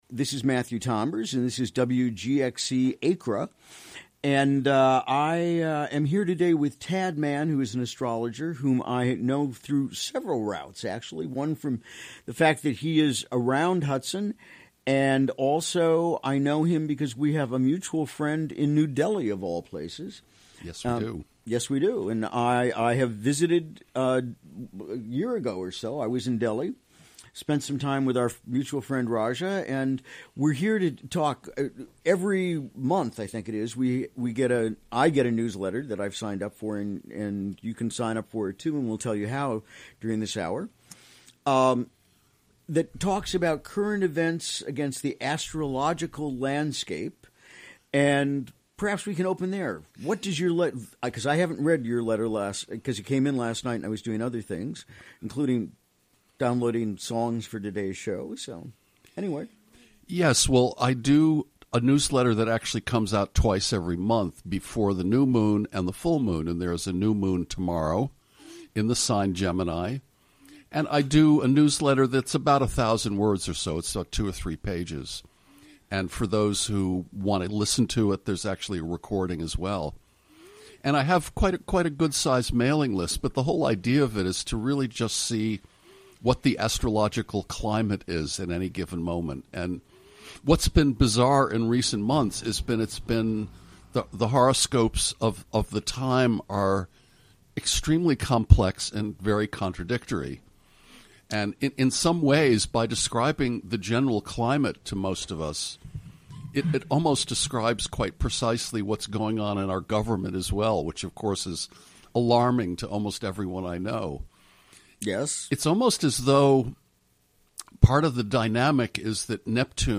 Recorded during the WGXC Morning Show on Wednesday, May 24.